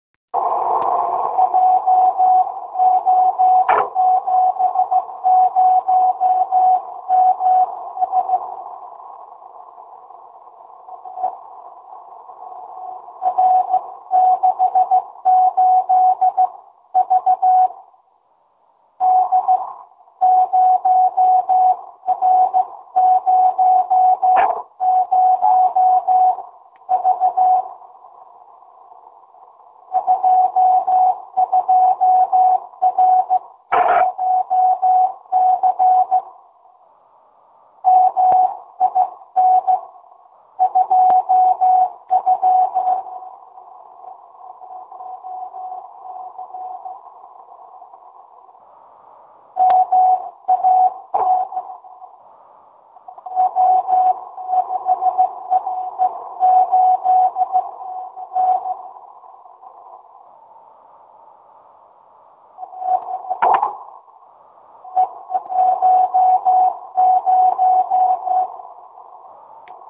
Sorry, nejsem zařízený na nahrávání, takže jen přes mikrofon z repro transceiveru do mobilu...